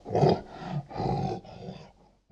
PixelPerfectionCE/assets/minecraft/sounds/mob/polarbear/idle3.ogg at mc116